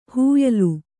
♪ huylu